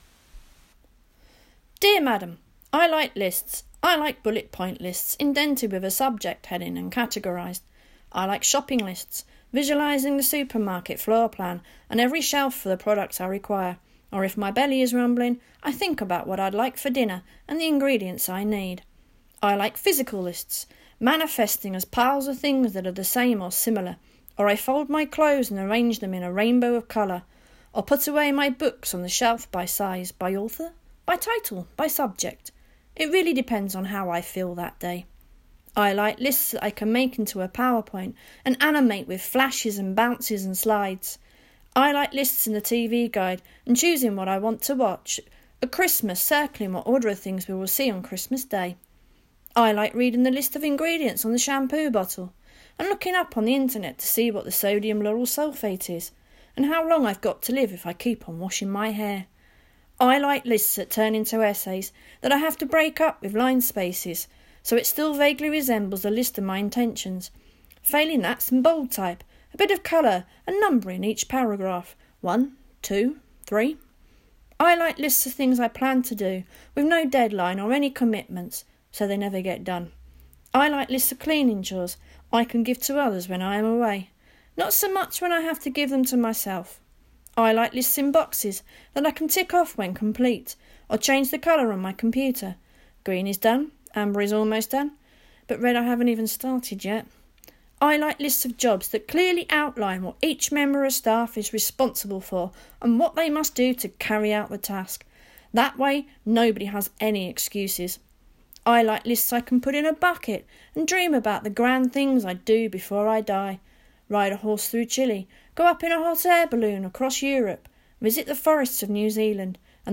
Below is an audio version, where I am reading out the letter